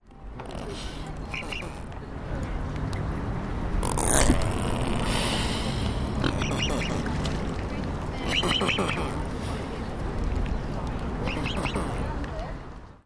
Cygnets at the pontoon